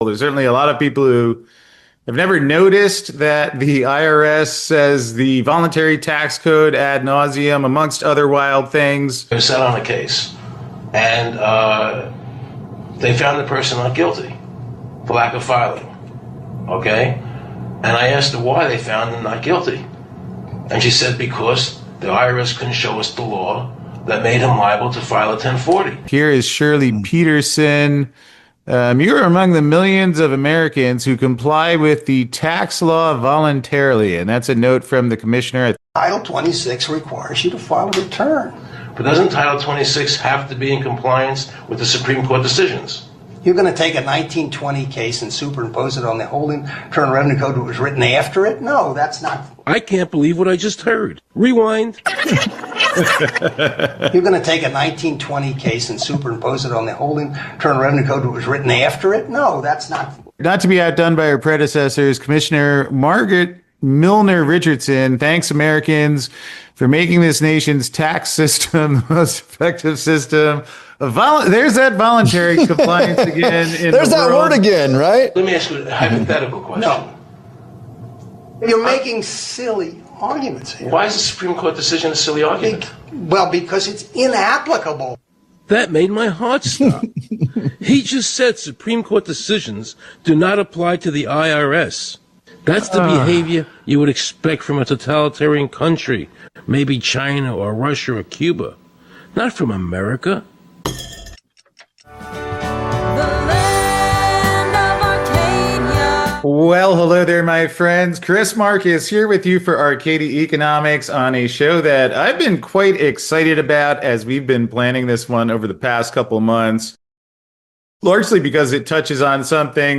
And let's just say you're going to want to see this interview, as it is truly eye-opening.